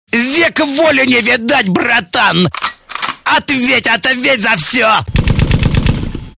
/64kbps) Описание: Век воли не видать, ответь братан, ответь ......(стрельба) ID 307837 Просмотрен 739 раз Скачан 64 раз Скопируй ссылку и скачай Fget-ом в течение 1-2 дней!